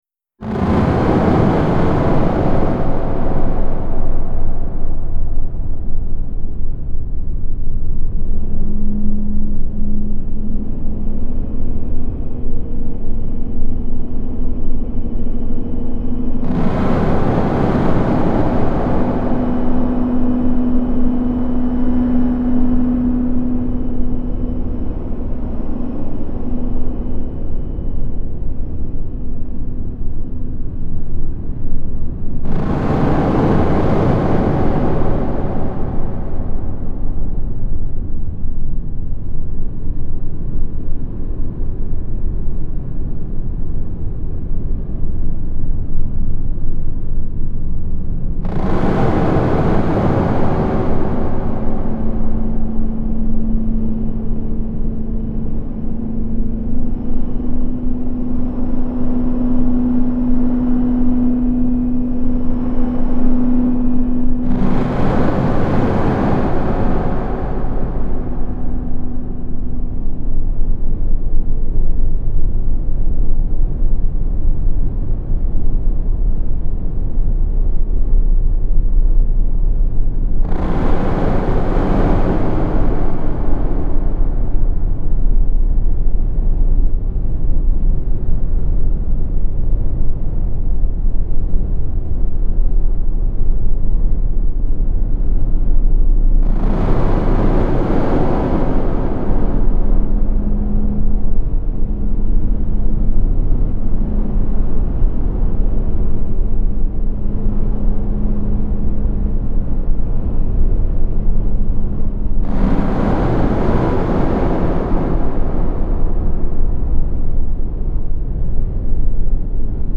The Internal Shock for Human Disorders and Deficiencies. A probable soundscape for whom who could hear.